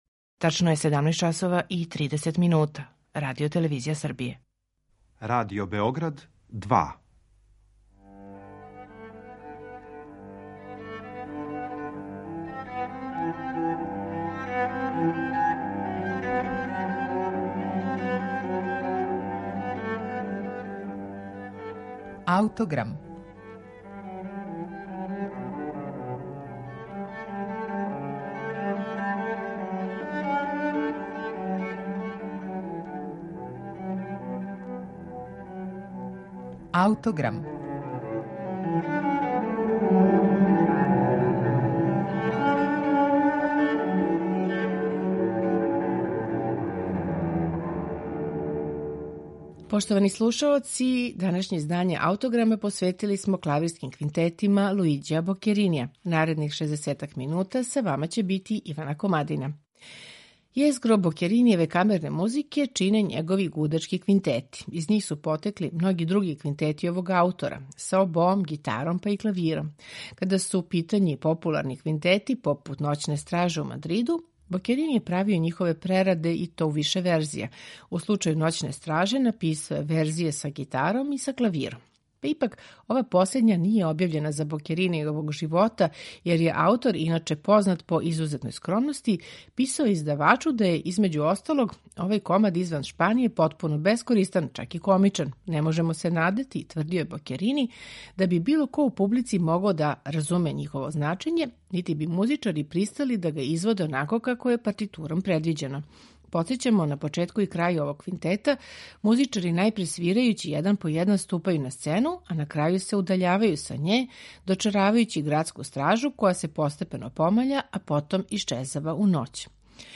У данашњем Аутограму квинтете из ових Бокеринијевих збирки слушамо у интерпретацији ансамбла Claveire , чији чланови свирају на оригиналним инструментима Бокеринијевог доба.
Вероватно је управо он био тај који је од Бокеринија 1797. године наручио шест квинтета за клавир и гудаче, у то време релативно нову форму.